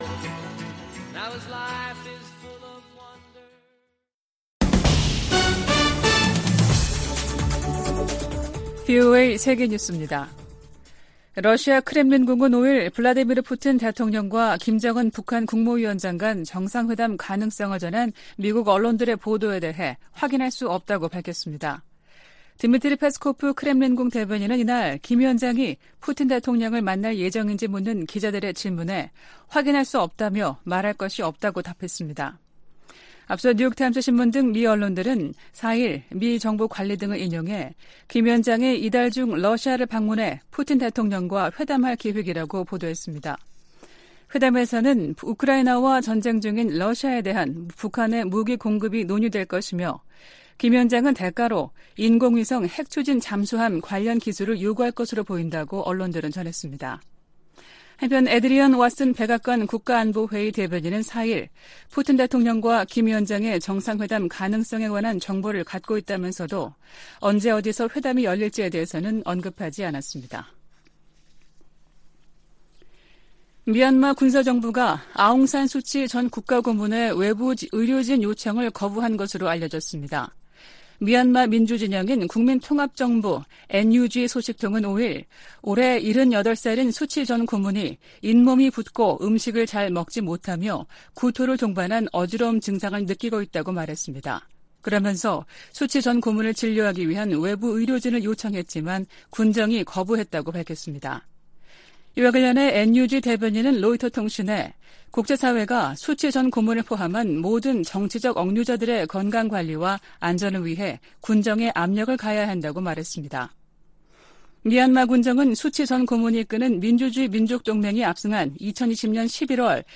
VOA 한국어 아침 뉴스 프로그램 '워싱턴 뉴스 광장' 2023년 9월 6일 방송입니다. 백악관은 북한 김정은 위원장의 러시아 방문에 관한 정보를 입수했다고 밝혔습니다. 미 국무부는 북한과 러시아의 연합 군사훈련 논의 가능성을 비판했습니다.